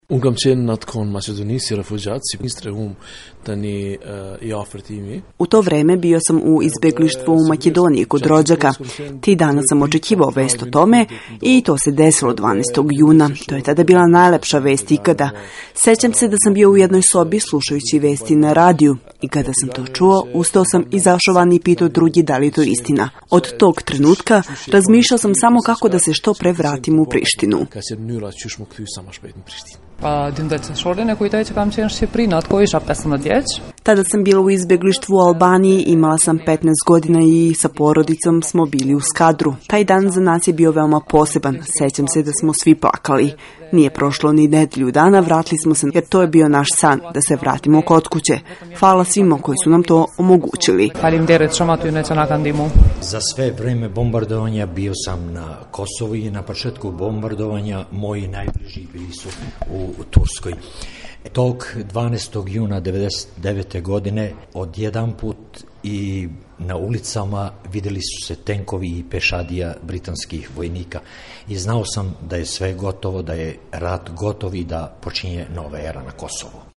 Pitali smo i građane Prištine albanske i turske nacionalnosti, kako se sećaju tog 12. juna 1999: